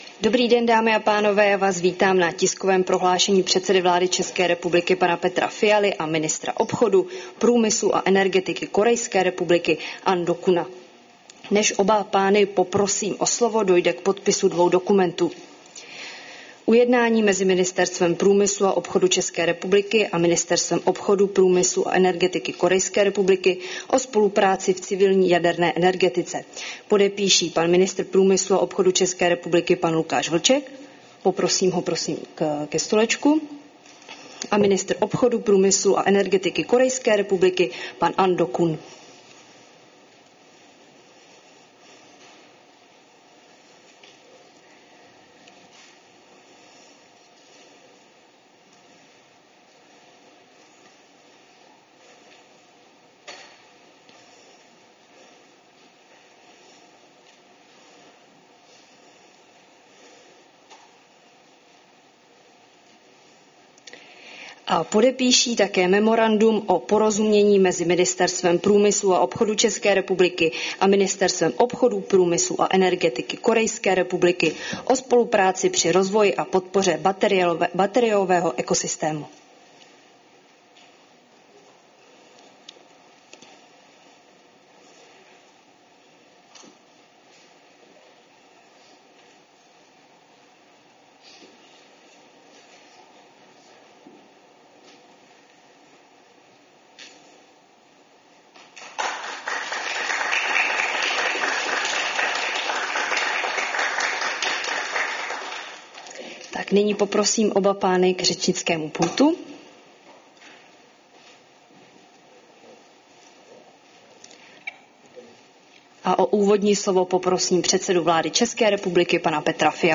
Společné prohlášení premiéra Petra Fialy a korejského ministra obchodu průmyslu a energetiky Ahn Dukgeuna